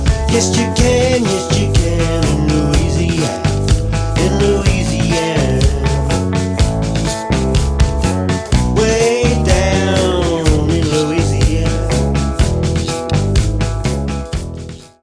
drums
backing vocals